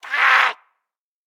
attack_2.ogg